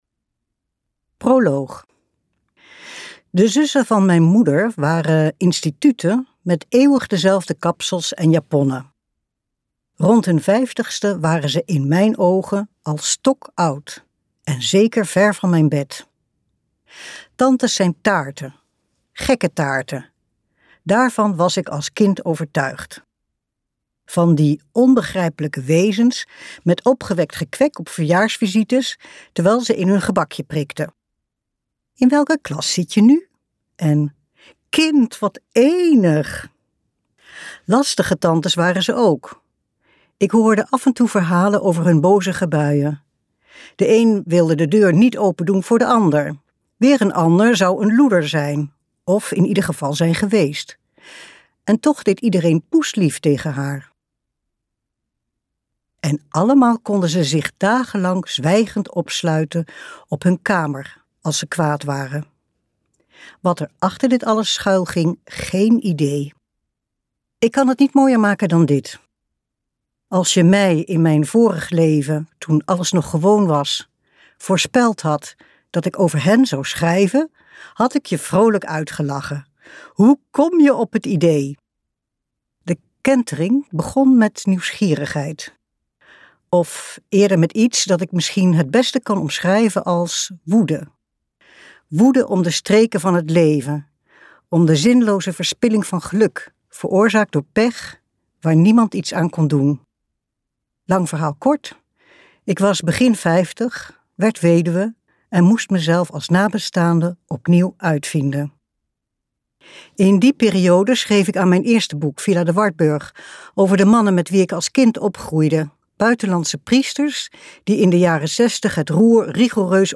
Zoals zussen zijn luisterboek | Ambo|Anthos Uitgevers